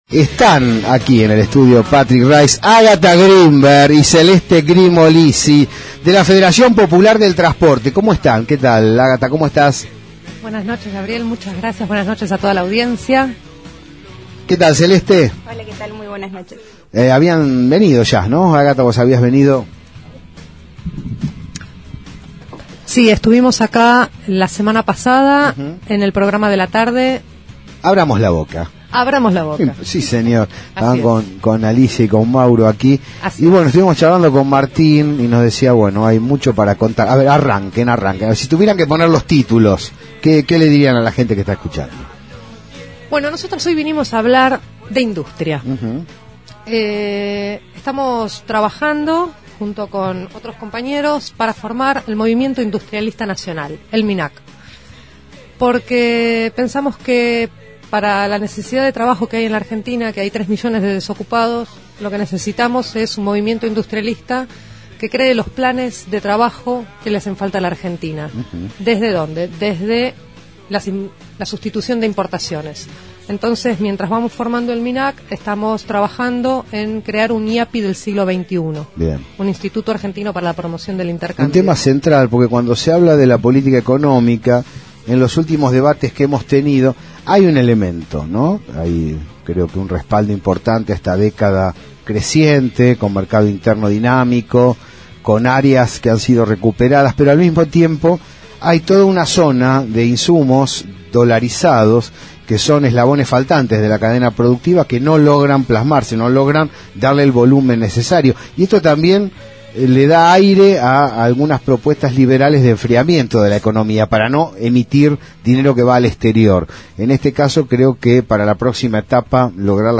inEl lunes pasado en La Señal se realizó un programa especial dedicado a la Industria Nacional.